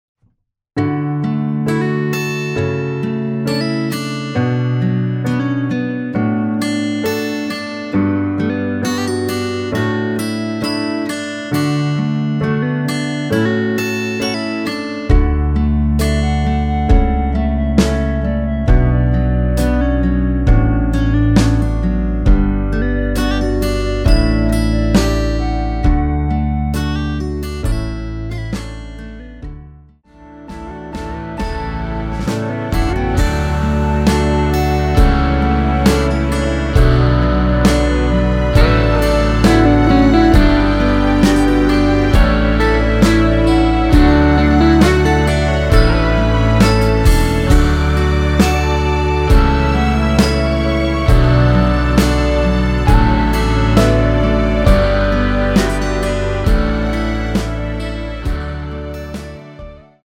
원키에서(+6)올린 멜로디 포함된 MR 입니다.(미리듣기 확인)
앞부분30초, 뒷부분30초씩 편집해서 올려 드리고 있습니다.
중간에 음이 끈어지고 다시 나오는 이유는